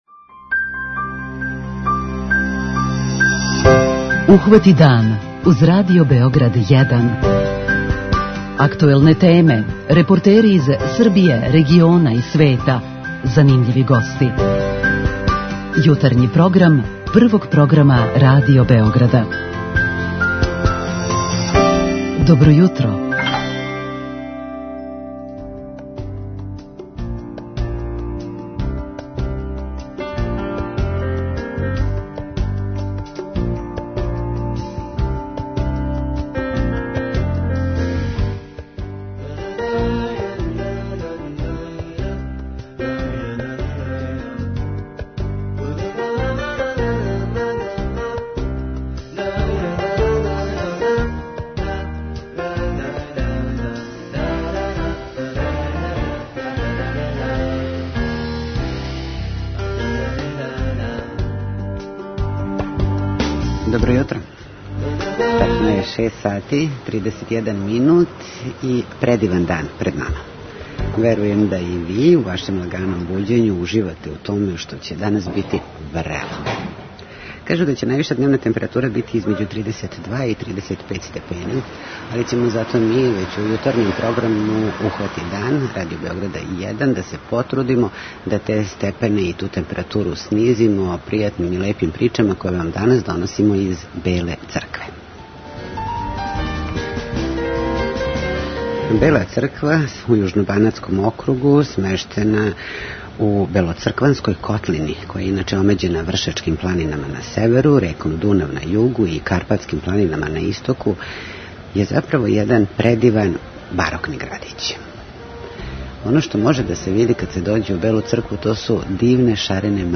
Ухвати дан - уживо из Беле Цркве